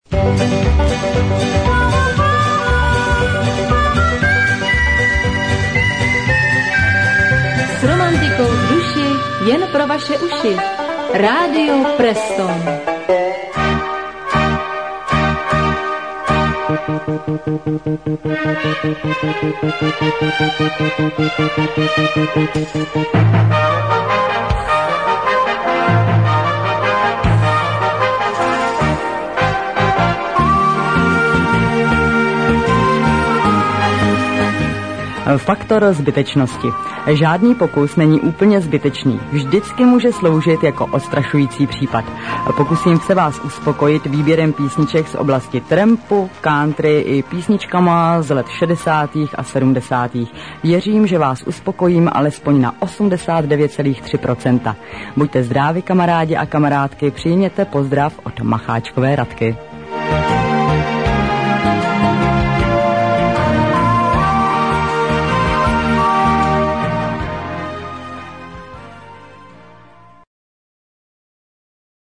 Na airchecku, co Vám nabízíme, je právě začátek Trampolíny, zachycený jedním z posluchačů někdy v roce 1999.
Před znělkou Trampolíny běží ještě jeden z pěkných jiglů Presstonu - Romantika.